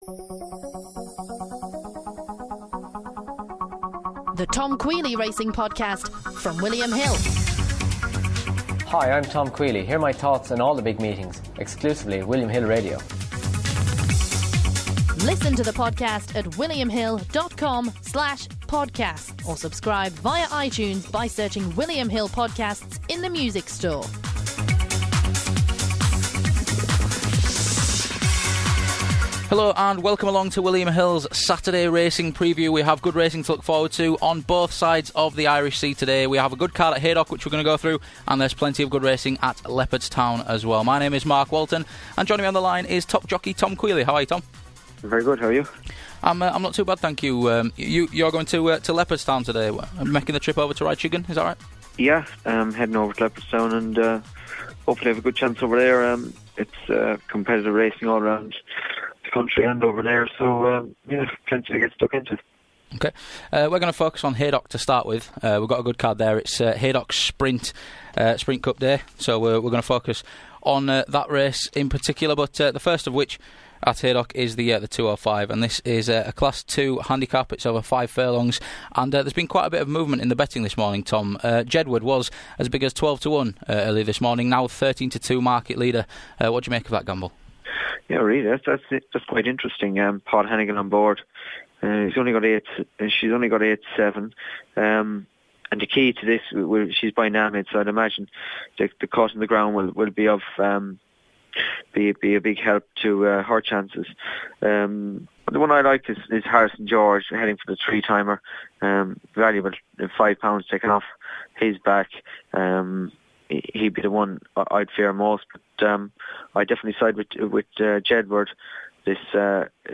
in the studio to look ahead to the action, assessing the best of the racing domestically and from over the Irish Sea.